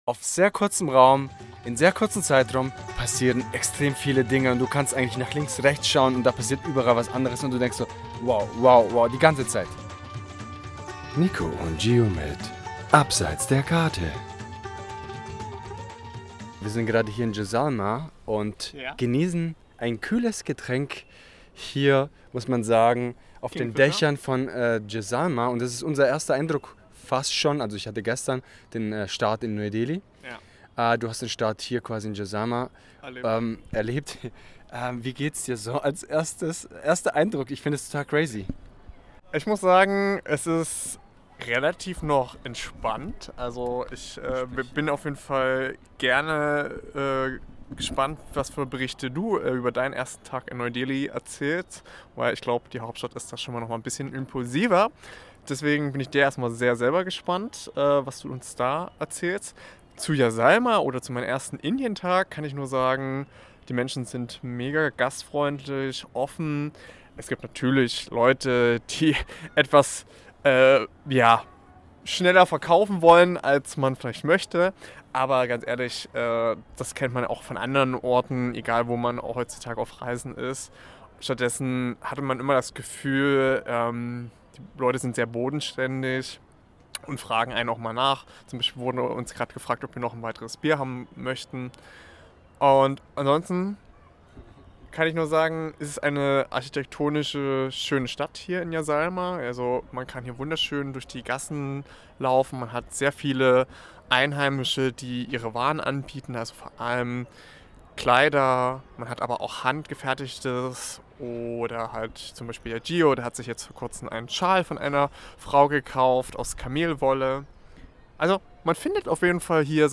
Die erste Erfahrung in Indien (Live aus Jaisalmer) 1/3 ~ Abseits der Karte Podcast
Die erste Episode dieser Art für Abseits der Karte, live aus Indien!